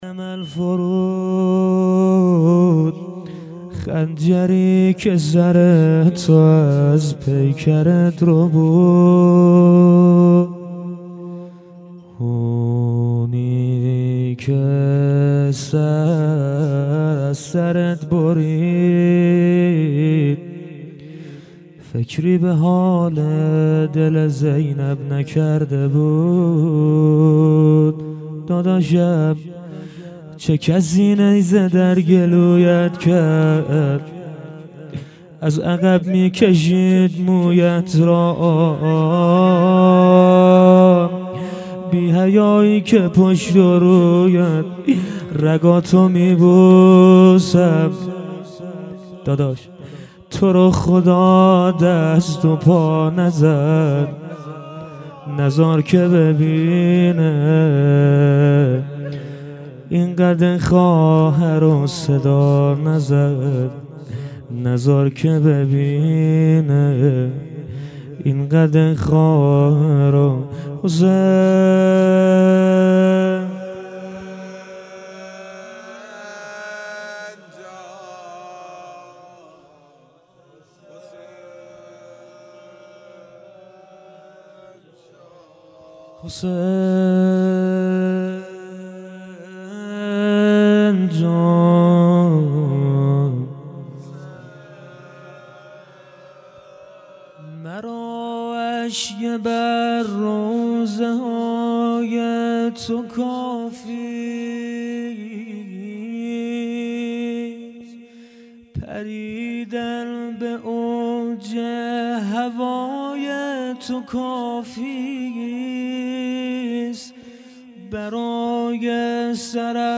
روضه زیبا